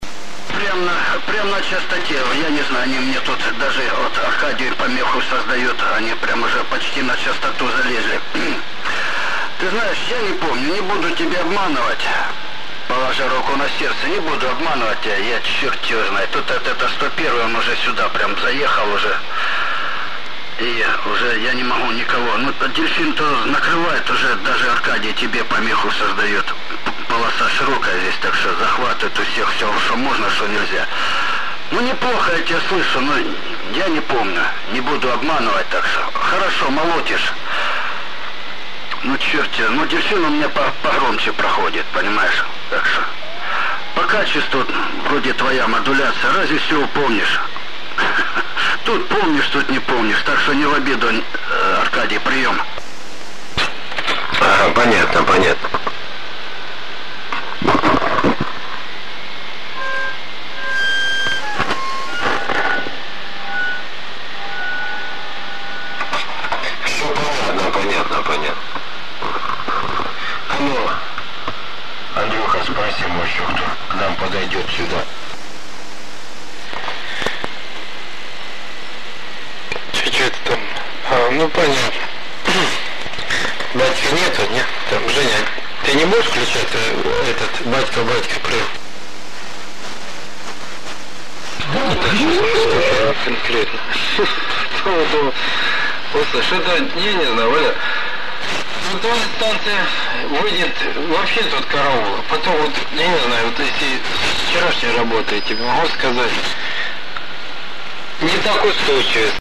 Умеет ли ТЕА6200 вашей редакции вот так принимать? Не напрягая ушей слушателя в паузах между включениями?
Например станция , которая включилась потом , идет с уровнем значительно большим.
В приемнике у меня выставлено совсем немного усиления, включен атт -20 дб, на это радио можно услышать то, что у теа6200 тонет в шумах увч, а если отключить увч - то и не слышно вовсе.